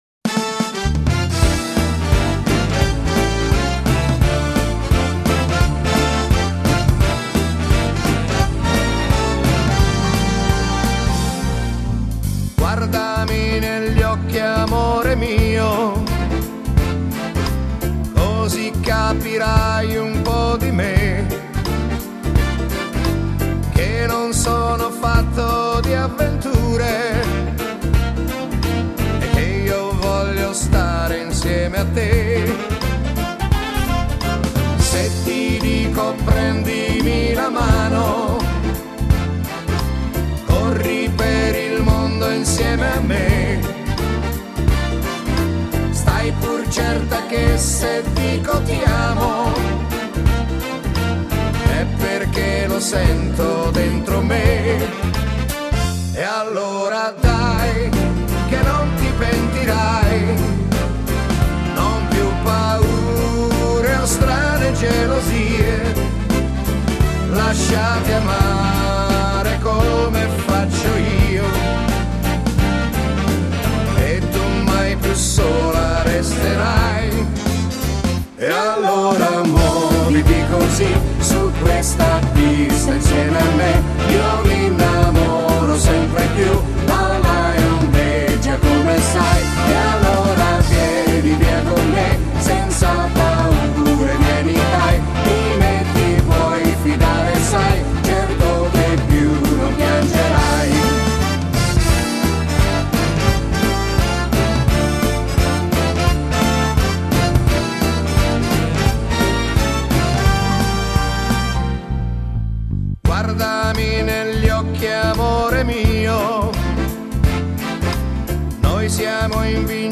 Genere: Fox trot